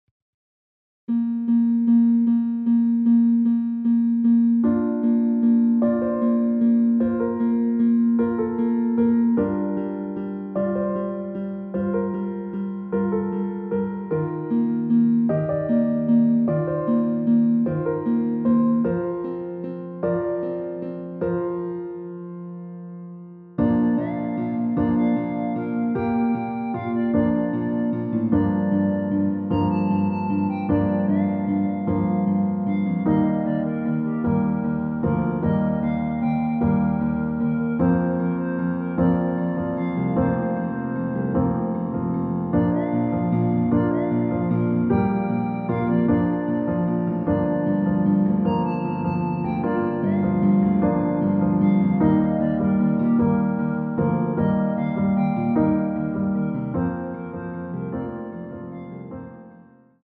원키에서(-2)내린 멜로디 포함된 MR입니다.(미리듣기 확인)
Bb
앞부분30초, 뒷부분30초씩 편집해서 올려 드리고 있습니다.
중간에 음이 끈어지고 다시 나오는 이유는